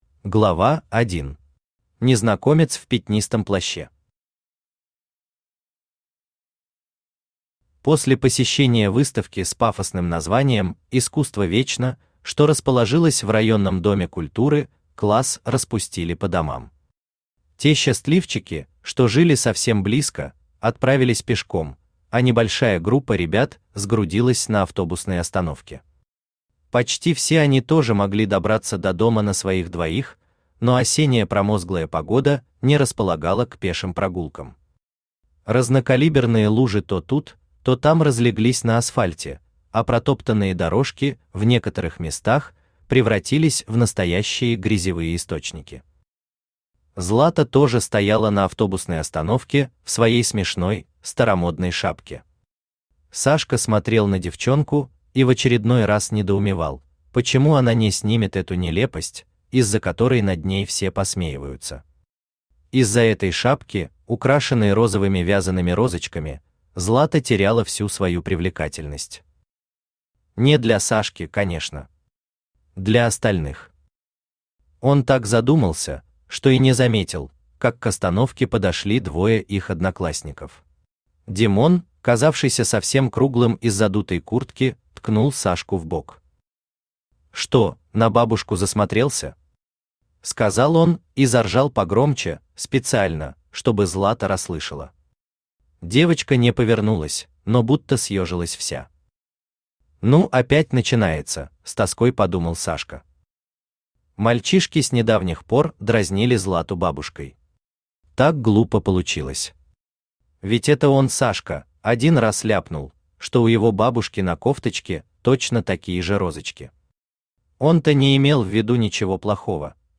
Аудиокнига Дай мне руку | Библиотека аудиокниг
Aудиокнига Дай мне руку Автор Юлия Иванова Читает аудиокнигу Авточтец ЛитРес.